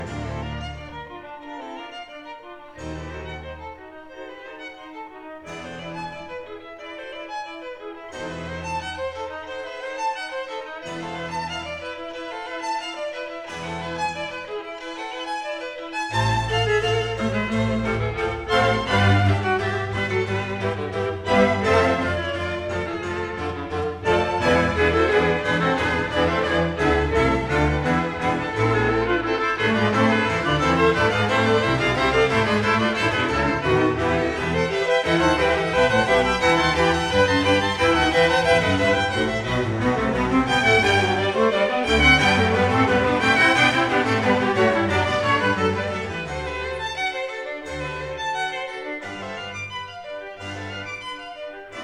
one instrument to a part